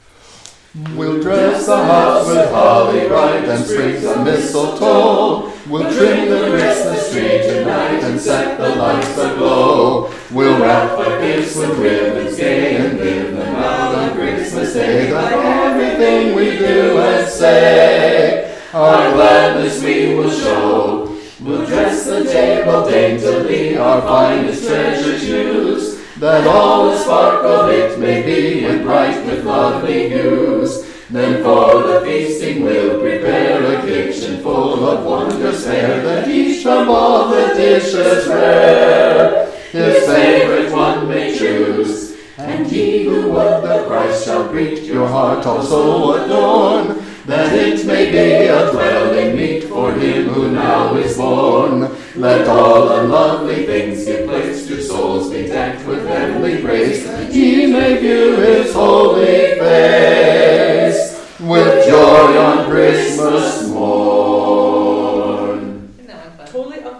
carol